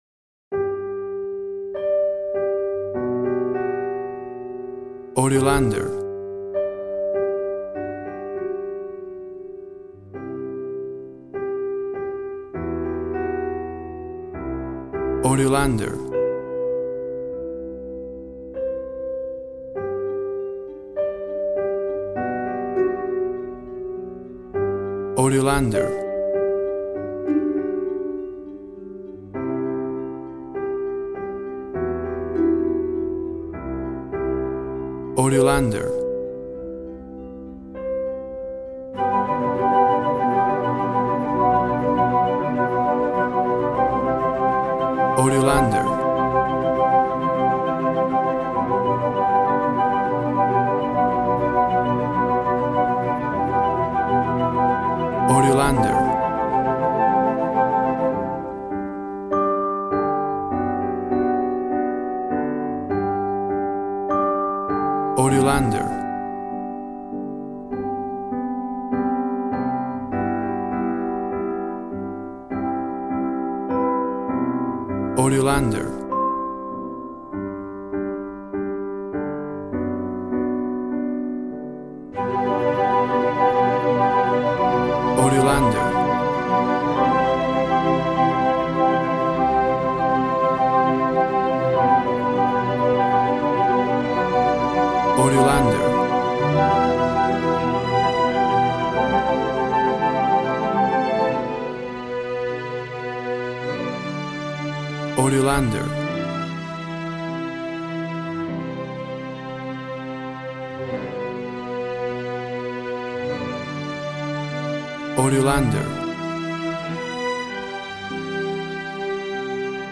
Regal and romantic, a classy piece of classical music.
Tempo (BPM) 100